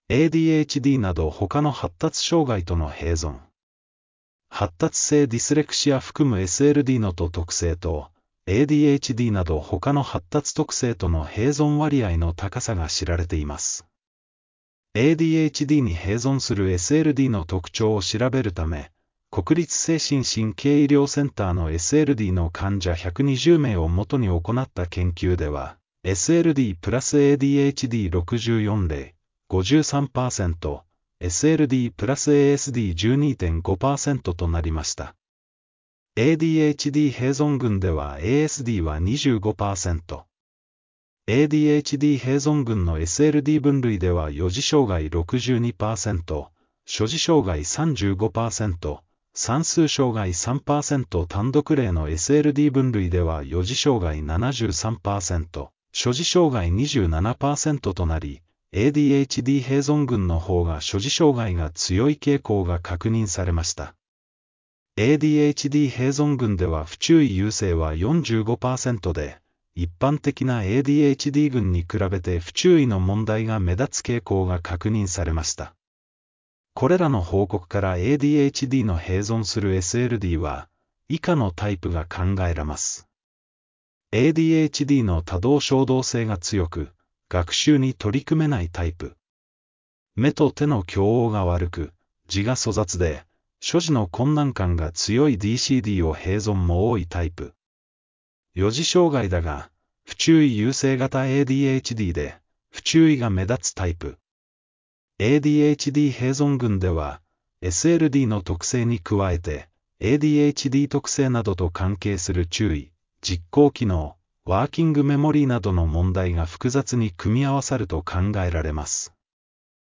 （音声ガイダンス）